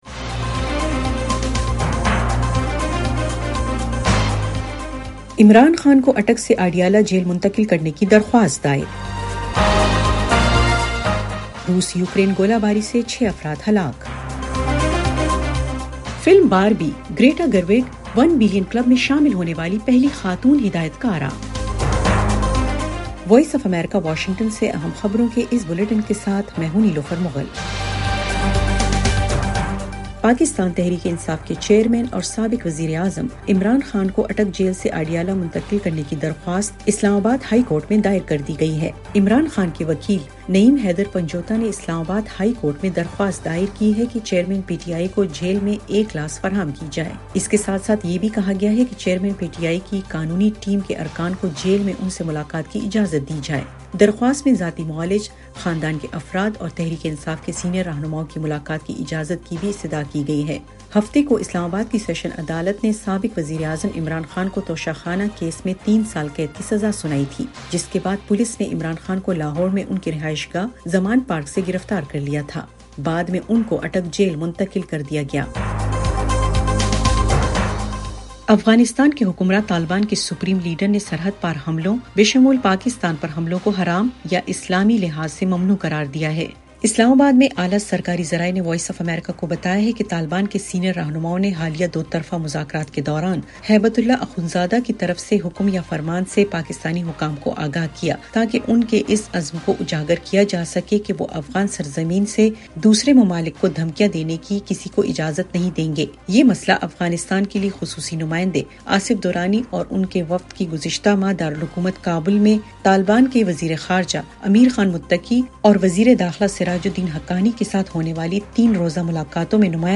ایف ایم ریڈیو نیوز بلیٹن : رات 8 بجے